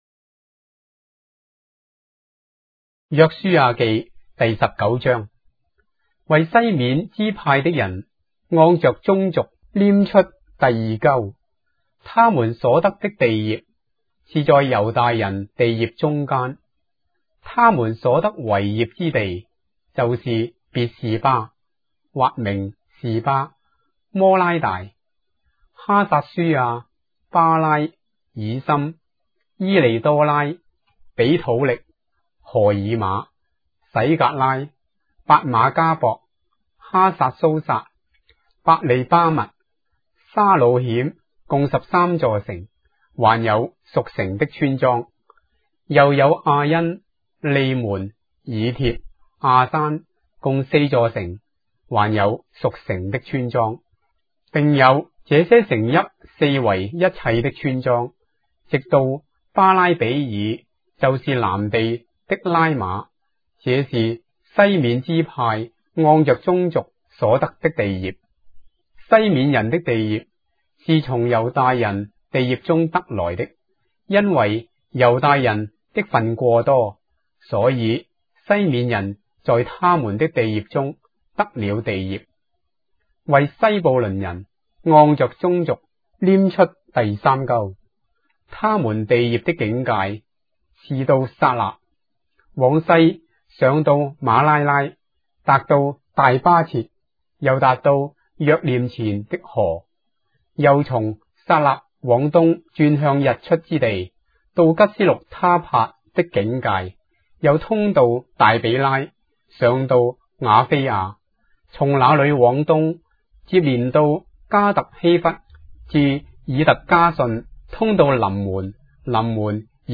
章的聖經在中國的語言，音頻旁白- Joshua, chapter 19 of the Holy Bible in Traditional Chinese